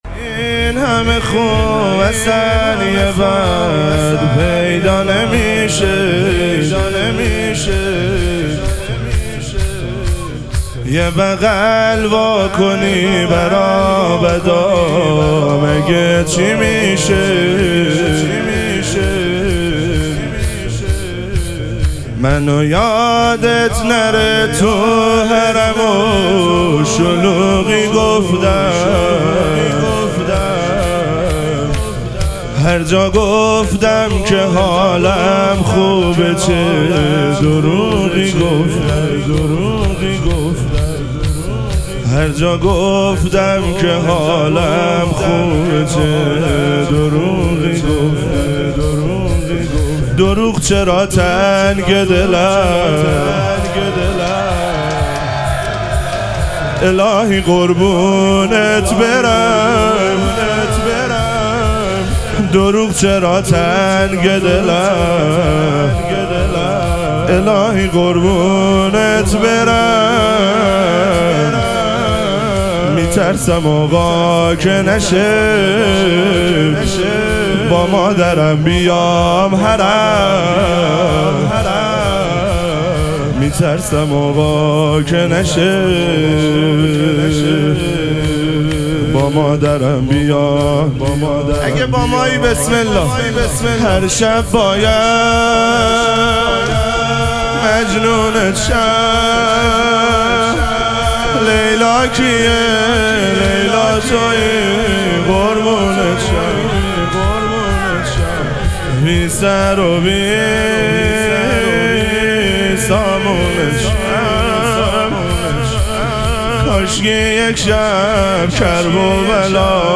ظهور وجود مقدس امام سجاد علیه السلام - شور